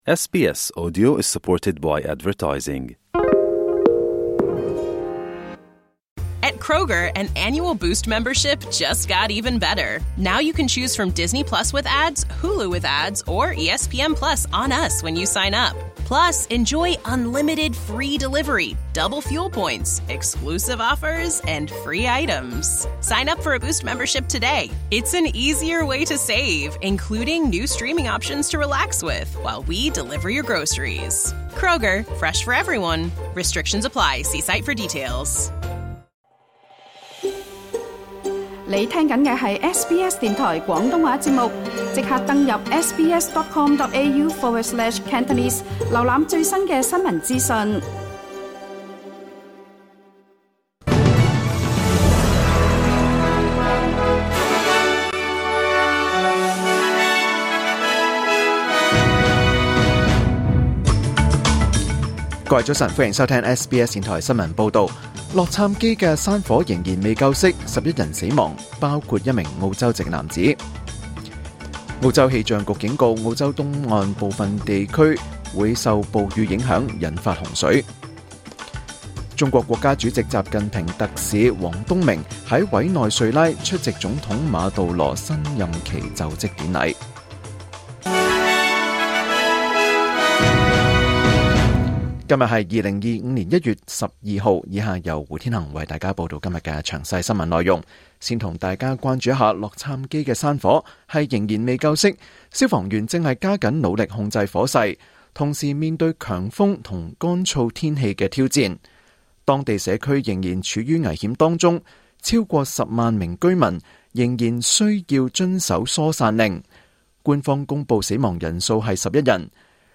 2025 年 1 月 12 日 SBS 廣東話節目詳盡早晨新聞報道。